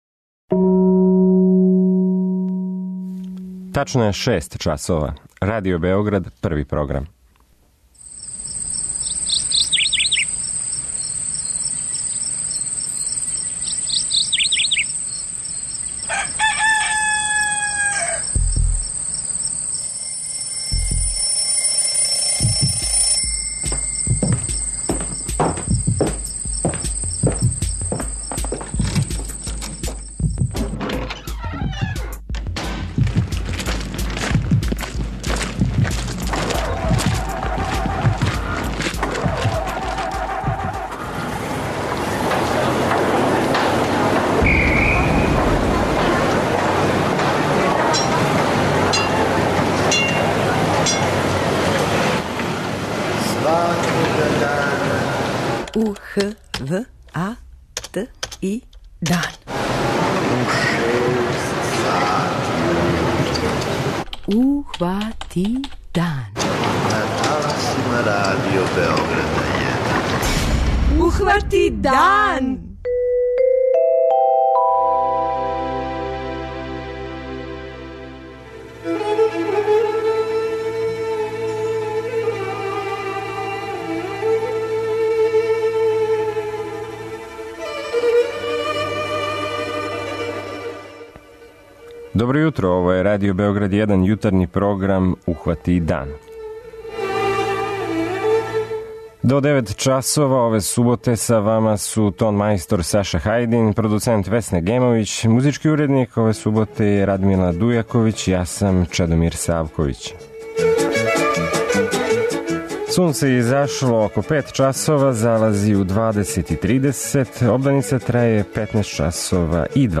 Овог јутра наш репортер јавиће се из Сребренице. Тамо се одржава комеморација страдалим Бошњацима.
преузми : 85.87 MB Ухвати дан Autor: Група аутора Јутарњи програм Радио Београда 1!